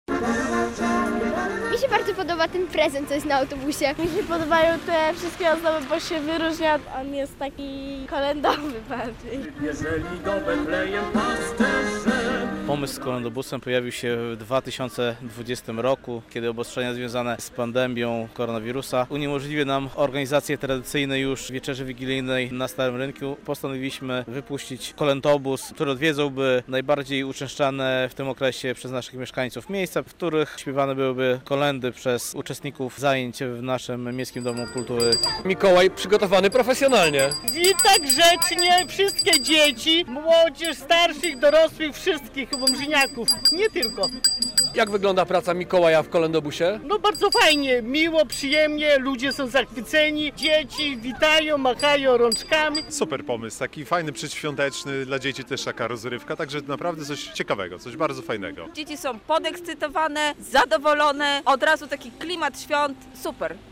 To autobus miejski, który odwiedza popularne wśród mieszkańców Łomży miejsca. A podczas dłuższych niż zwykle przystanków występują młodzi wokaliści śpiewający kolędy.
- Pomysł z Kolędobusem pojawił się 2020 roku, kiedy obostrzenia związane z pandemią koronawirusa uniemożliwiły nam organizację tradycyjnej już wieczerzy wigilijnej na Starym Rynku.